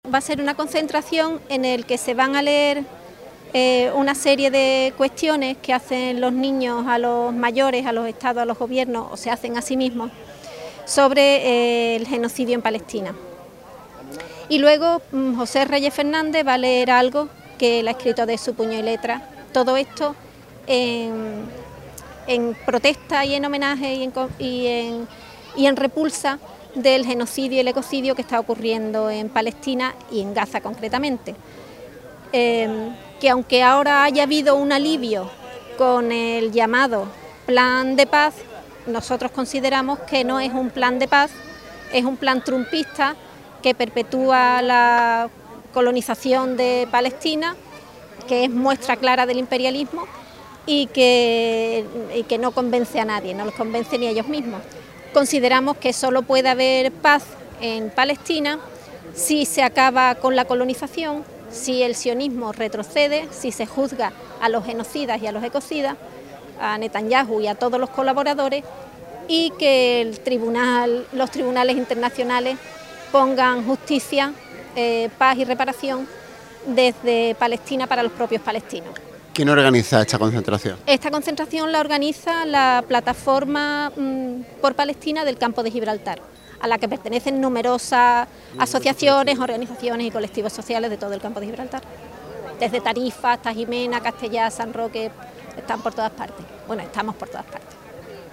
Concentración pro Palestina celebrada en la Alameda Alfonso XI